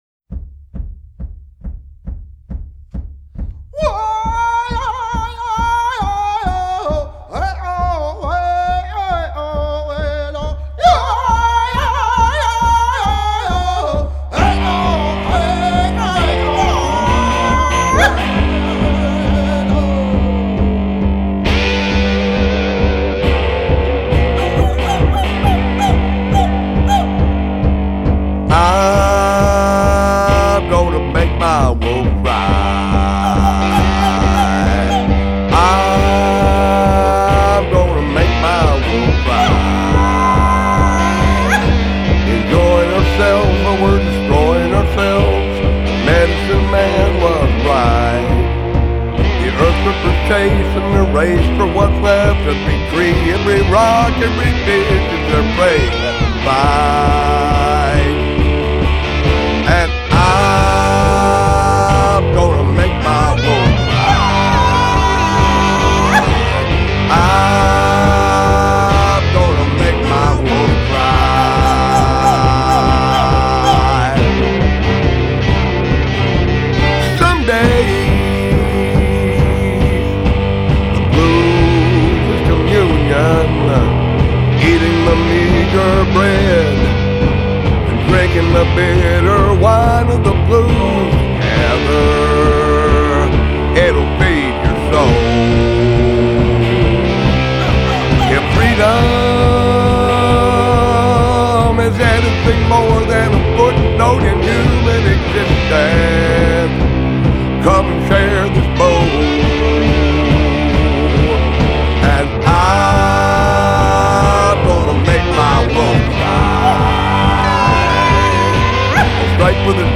vocals/harmonica/slide guitar
drums/percussion
upright bass
electric guitar
electric bass
piano/clarinet/accordion/mandolin
fiddle